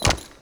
trot1.wav